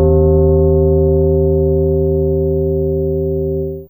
ChimesC1C2.wav